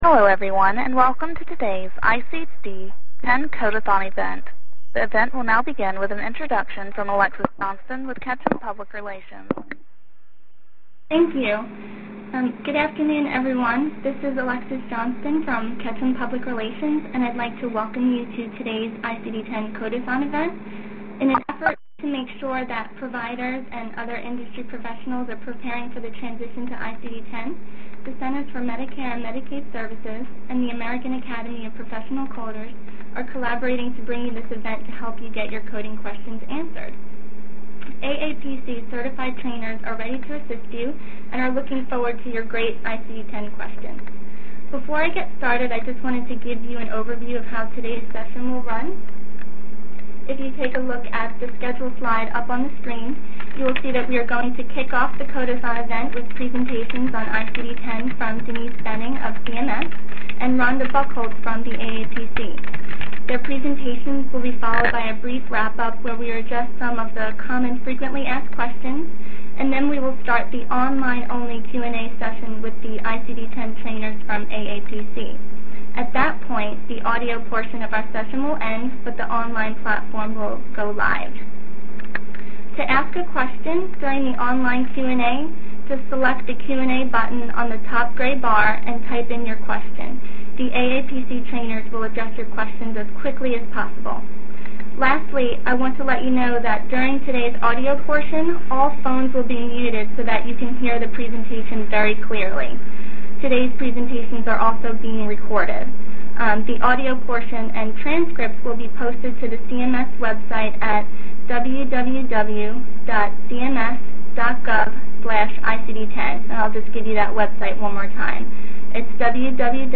Posted materials include: Presentations from AAPC and CMS on ICD-10 and Version 5010 A transcript and audio of the presentations given during the webinar These materials should be helpful in getting informed and learning about the transitions to Version 5010 and ICD-10.